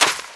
High Quality Footsteps
STEPS Sand, Run 29.wav